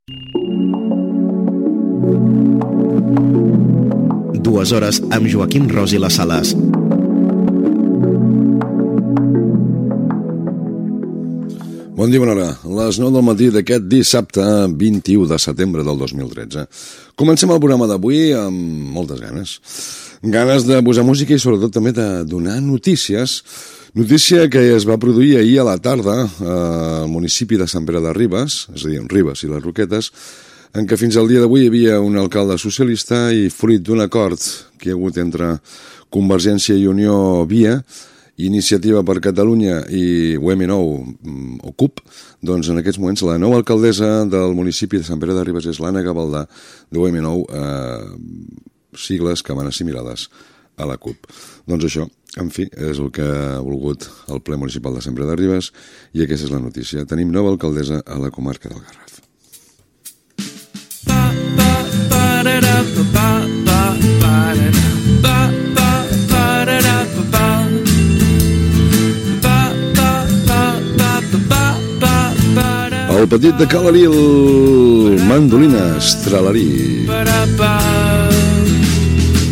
Pública municipal
Careta, inici del programa, comentari sobre la nova alcaldessa de Sant Pere de Ribas.
Entreteniment